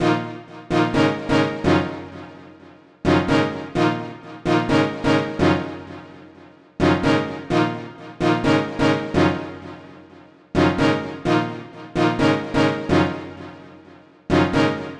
无线电就绪的黄铜
Tag: 128 bpm RnB Loops Brass Loops 2.52 MB wav Key : Unknown